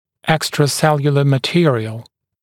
[ˌekstrə’seljələ mə’tɪərɪəl][ˌэкстрэ’сэлйэлэ мэ’тиэриэл]внеклеточный материал